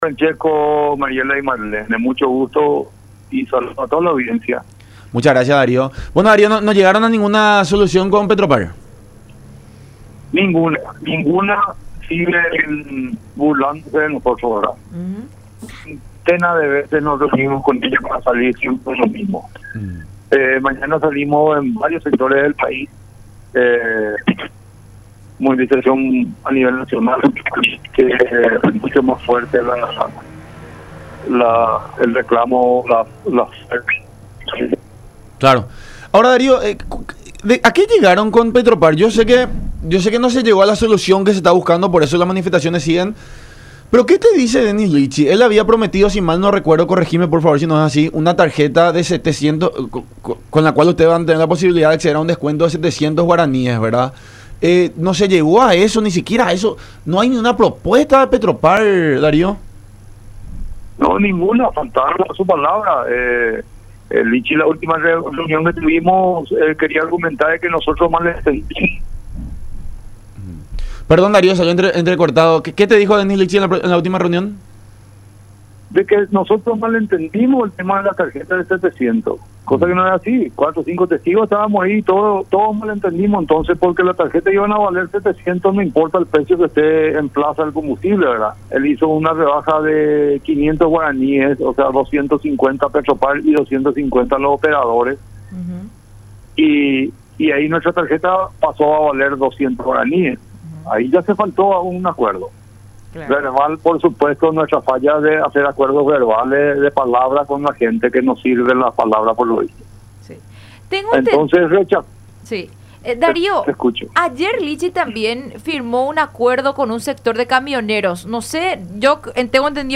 en charla con La Unión Hace La Fuerza por Unión TV y radio La Unión